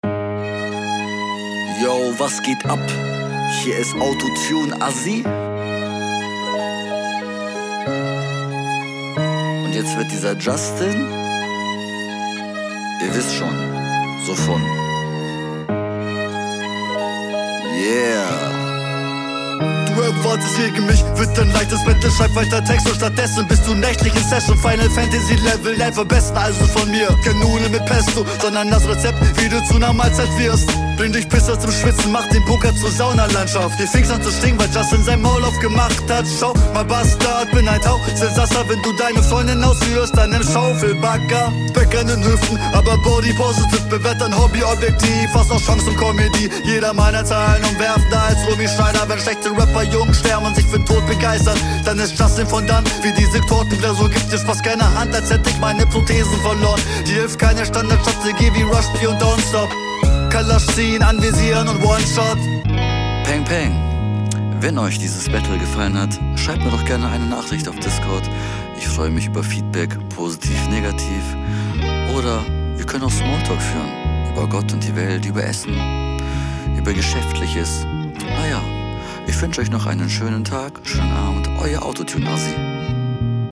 Auch ein cooler Beat!